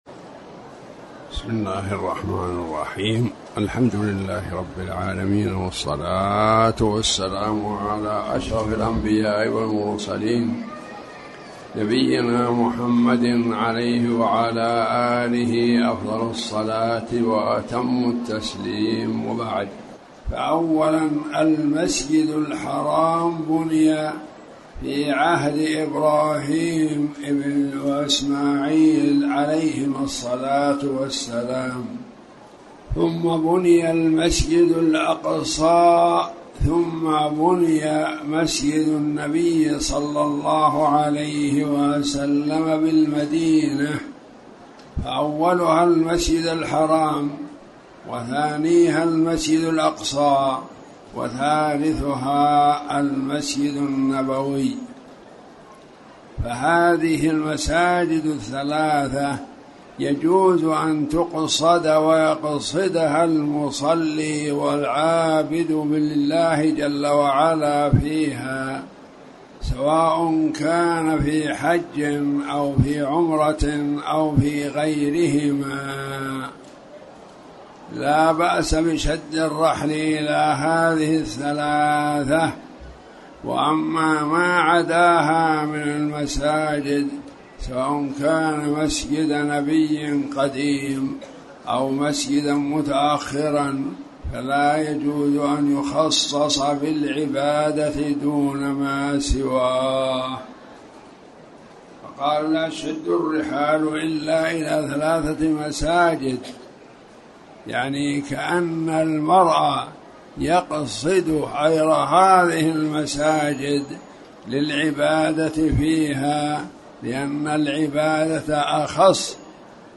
تاريخ النشر ٥ شعبان ١٤٣٩ هـ المكان: المسجد الحرام الشيخ